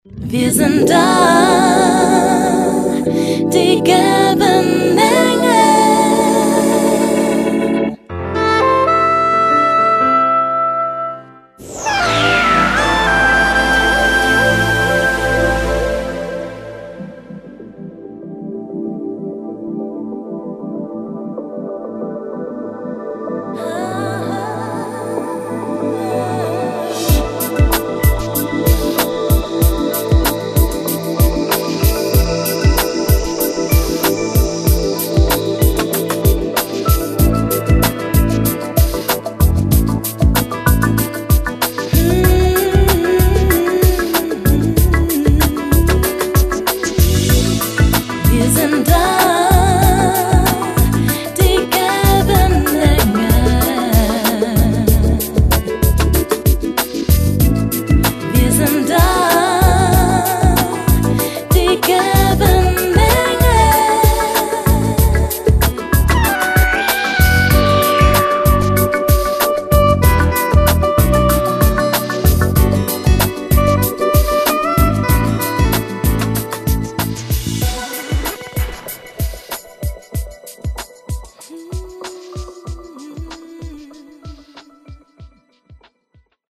Audiologo/Soundscape